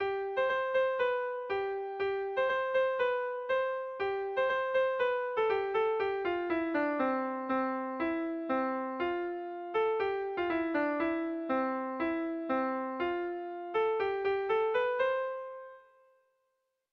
Dantzakoa
AAB